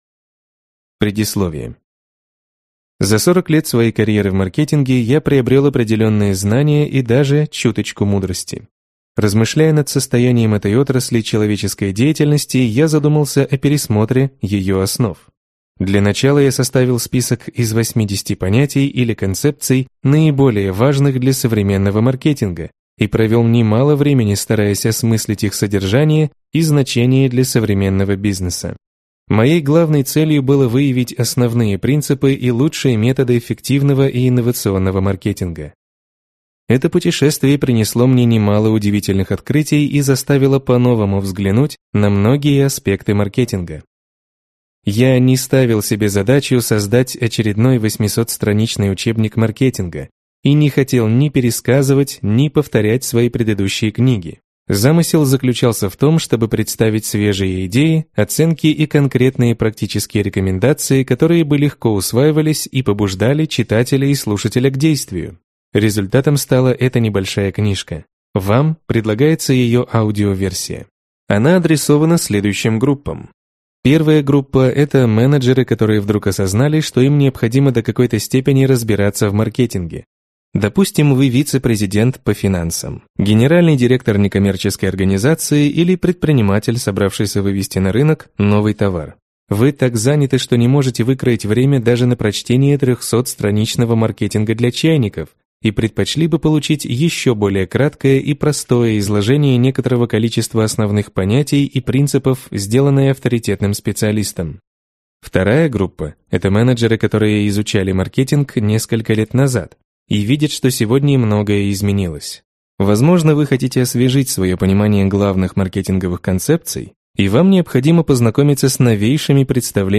Aудиокнига Маркетинг от А до Я